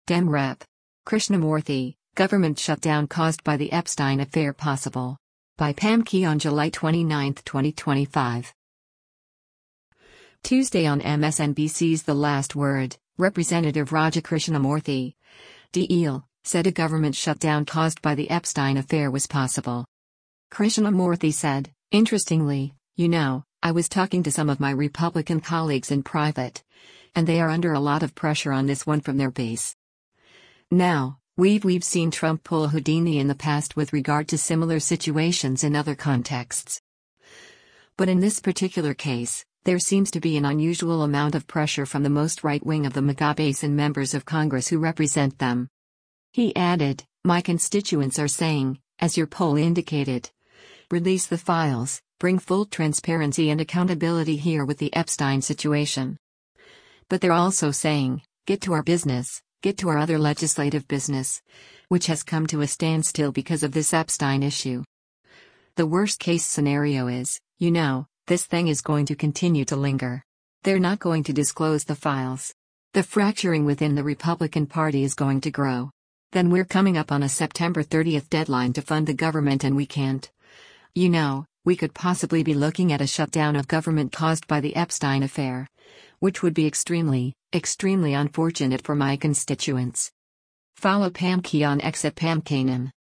Tuesday on MSNBC’s “The Last Word,” Rep. Raja Krishnamoorthi (D-IL) said a government shutdown caused by the Epstein affair was possible.